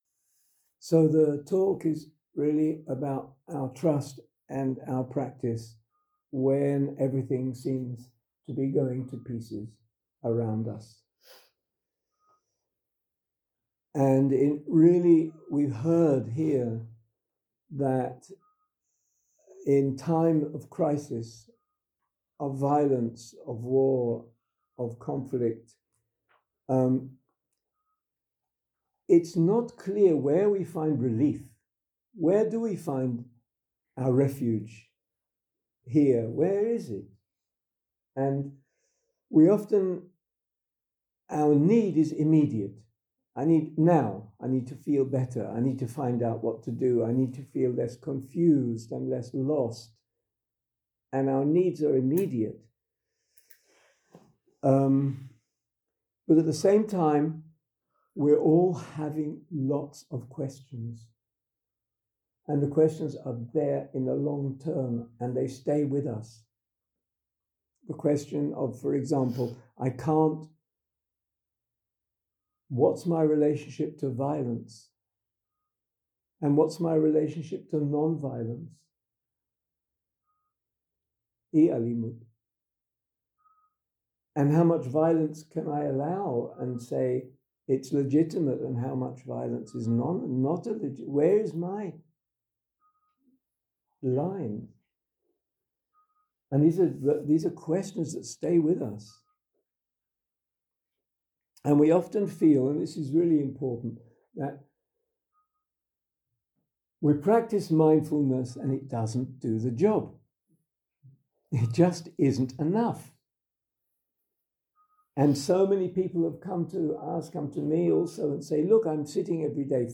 יום 1 - הקלטה 1 - ערב - שיחת דהרמה - Trust and Spiritual Practice in Times of Conflict 0:00 0:00 סוג ההקלטה: Dharma type: Dharma Talks שפת ההקלטה: Dharma talk language: Hebrew